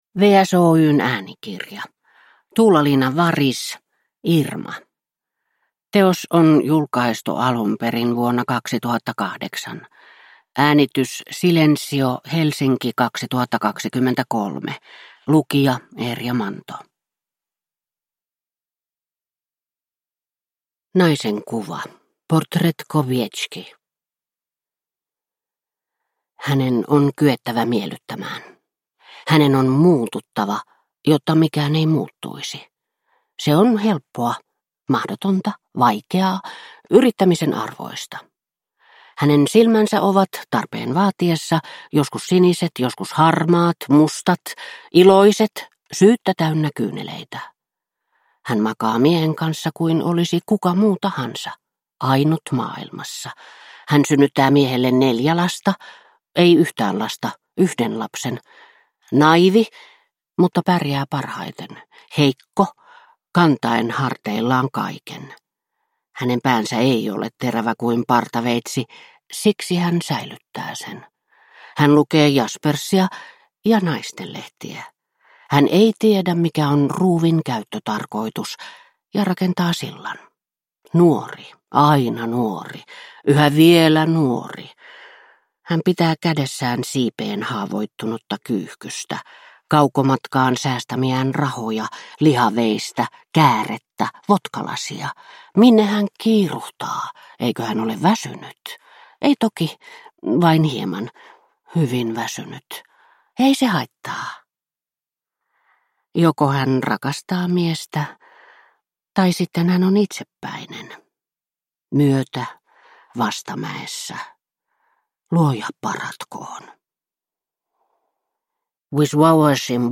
Irma – Ljudbok – Laddas ner